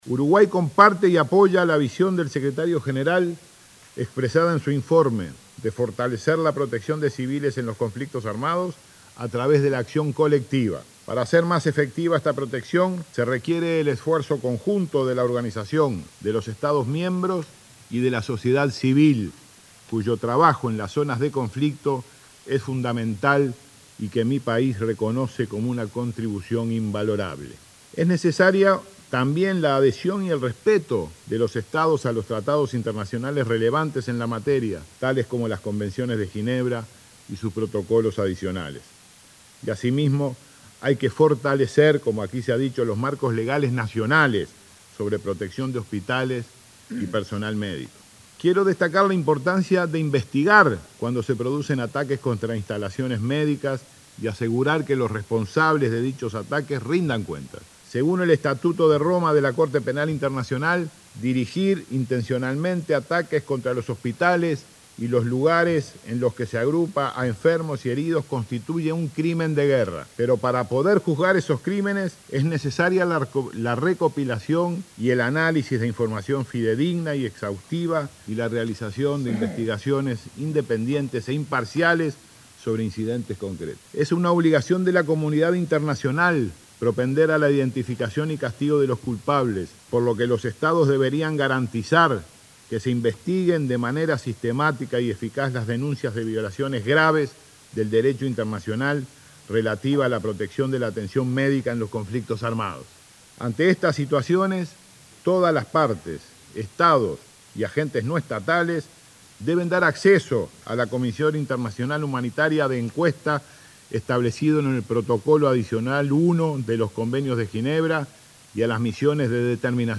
“Es una obligación de la comunidad internacional propender a la identificación y castigo de los culpables”, dijo el canciller Rodolfo Nin Novoa ante el Consejo de Seguridad de ONU, en referencia a la importancia de investigar atentados a instalaciones médicas y asegurar que los responsables rindan cuenta. Afirmó que Uruguay compartió visión de ONU de fortalecer protección de civiles mediante acción colectiva de los países.